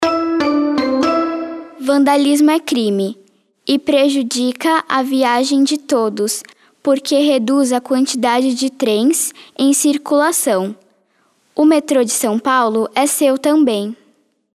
OUÇA: Transportes de São Paulo recebem avisos sonoros gravados por crianças
Já no Metrô, entre 11 e 31 de outubro, os avisos sonoros educativos e de gentileza são feitos nas vozes de nove crianças.